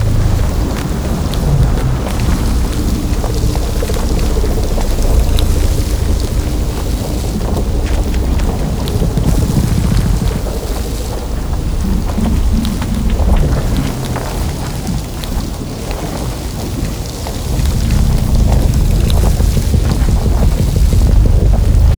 springboard_idle.ogg